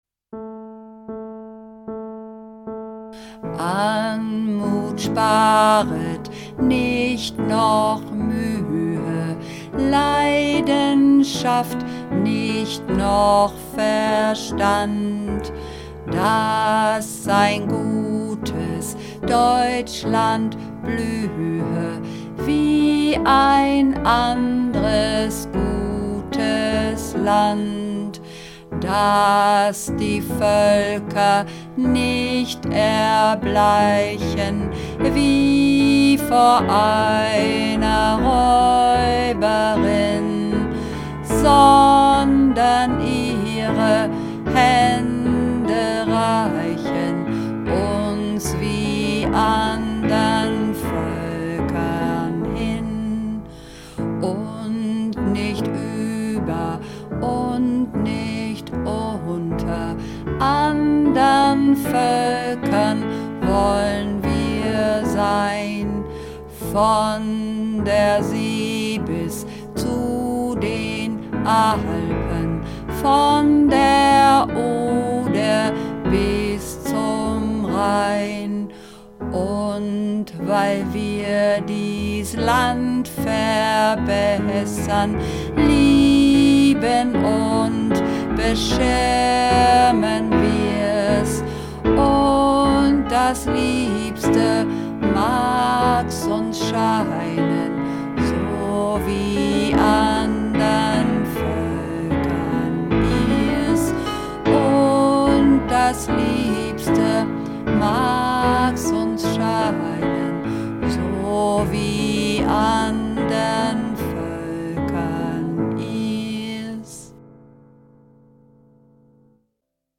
Übungsaufnahmen - Kinderhymne
Kinderhymne (Bass und Alt - Tief)
Kinderhymne__1_Bass_Tief.mp3